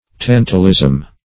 Tantalism \Tan"ta*lism\, n. [See Tantalize.]